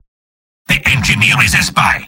Robot-filtered lines from MvM. This is an audio clip from the game Team Fortress 2 .
{{AudioTF2}} Category:Spy Robot audio responses You cannot overwrite this file.